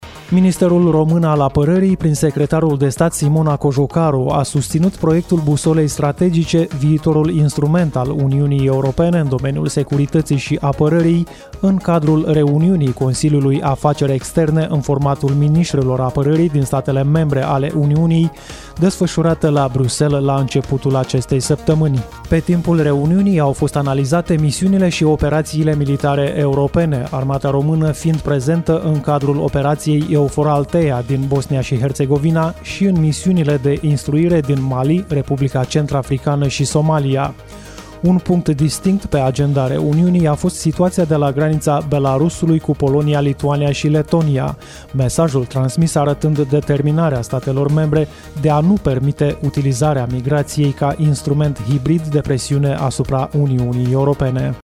MApN-Simona-Cojocaru-stire-3.mp3